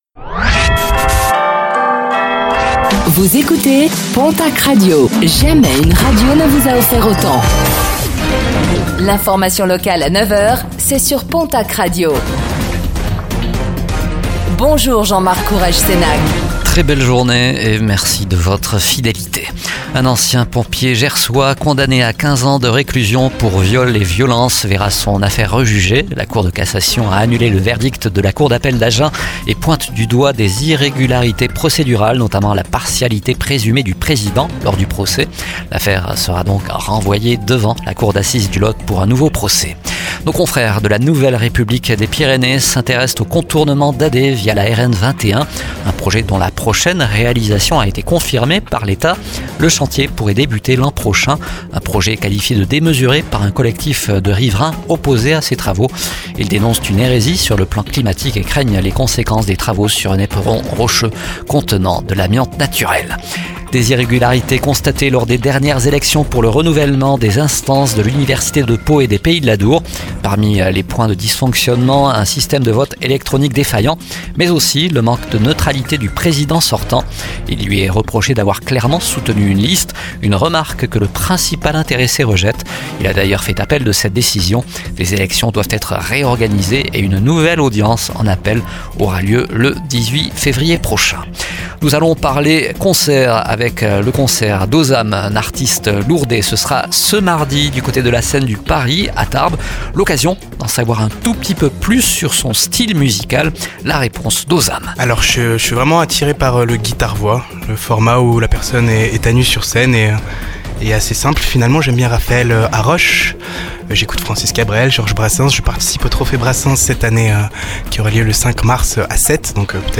Réécoutez le flash d'information locale de ce vendredi 10 janvier 2025